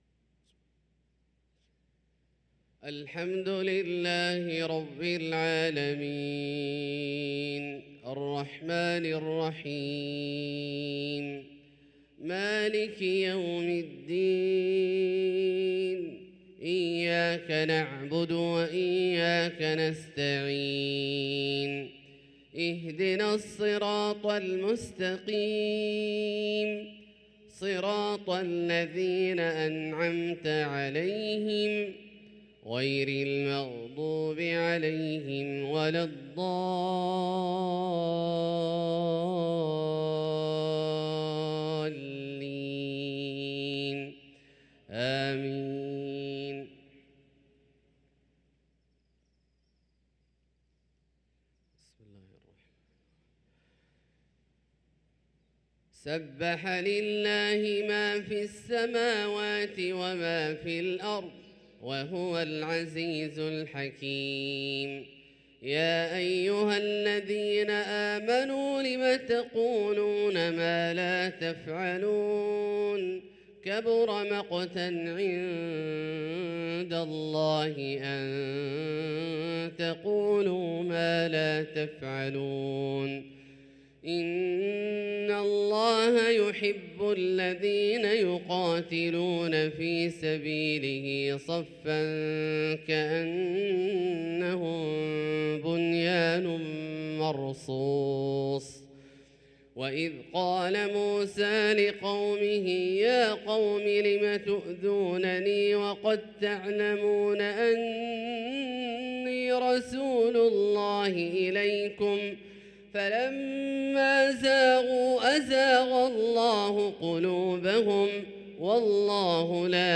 صلاة الفجر للقارئ عبدالله الجهني 15 ربيع الأول 1445 هـ
تِلَاوَات الْحَرَمَيْن .